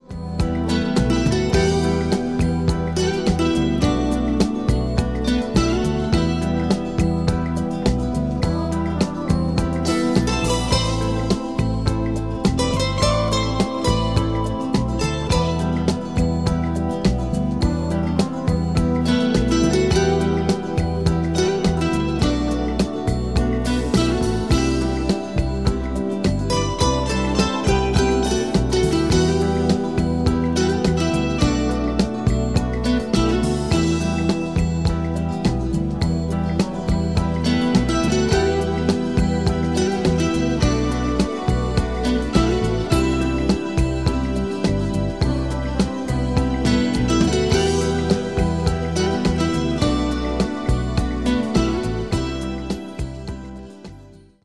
• Качество: 192, Stereo
инструментальные
мультиинструментальные
Немецкий композитор и музыкант, мультиинструменталист